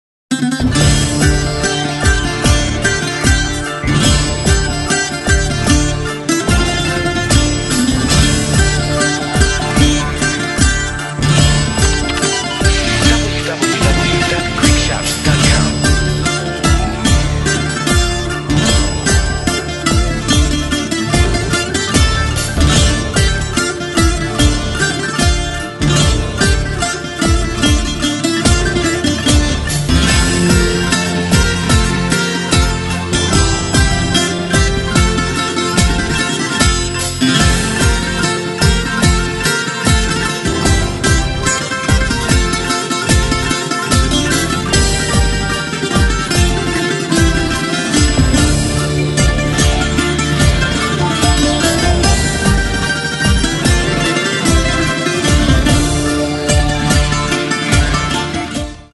18 Greek dance bouzouki hits
instrumental re-done